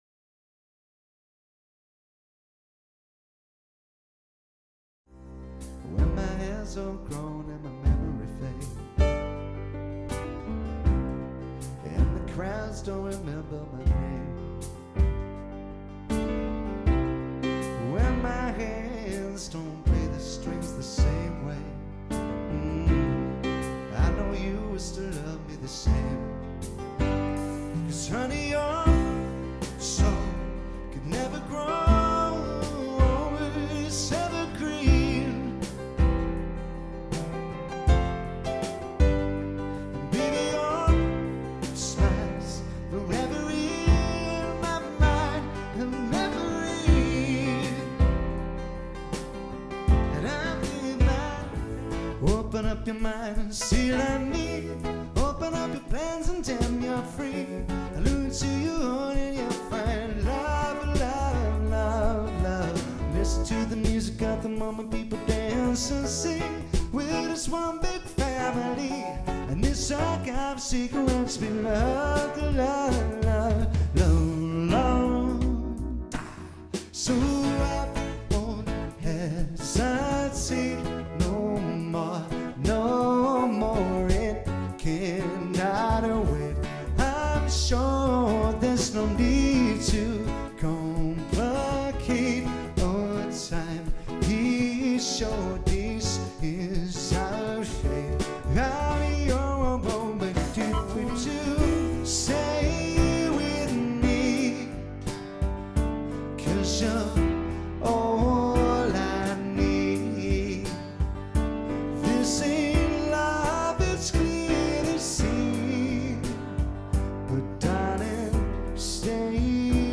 Brilliantly Talented Musicians and Vocalists